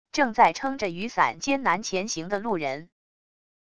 正在撑着雨伞艰难前行的路人wav音频